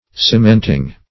cementing - definition of cementing - synonyms, pronunciation, spelling from Free Dictionary